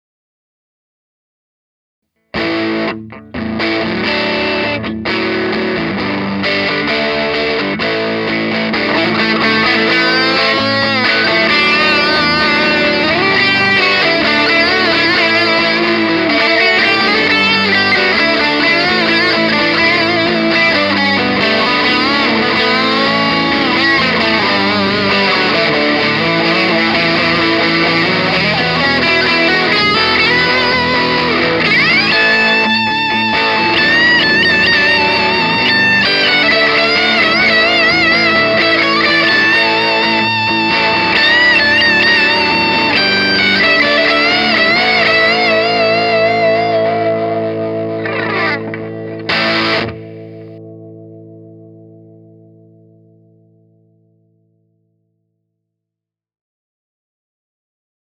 Note that these tracks are raw tracks. I used no EQ nor compression because I wanted to ensure that I’d capture all the dynamics of the amp.
Rock, Crunchy Rhythm (left), Solo (right)
I used a single mic – a Sennheiser e609 – positioned about 18″ from the cabinet pointed directly at the center of the cone. Part of why you might hear a little static is the ambient room noise from my garage.
sebago_tf_les_paul_od.mp3